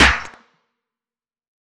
SNARE 5.wav